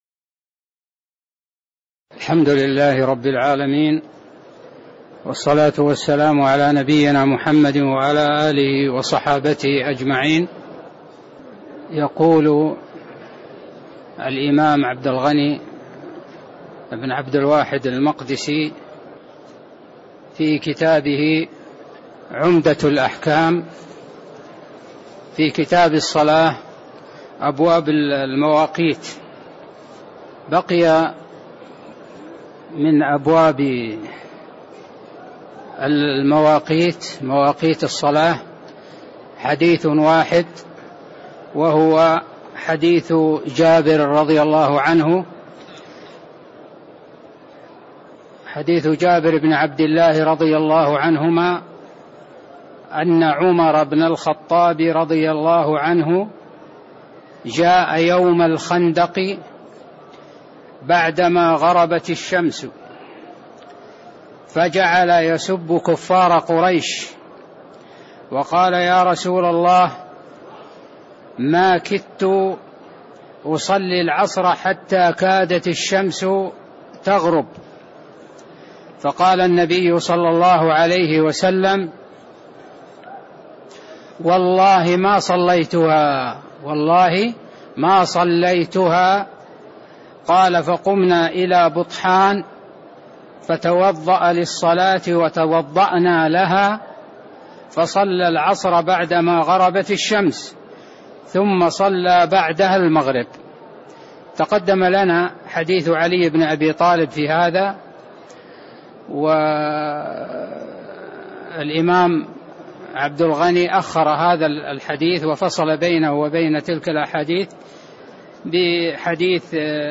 تاريخ النشر ١٣ شعبان ١٤٣٥ هـ المكان: المسجد النبوي الشيخ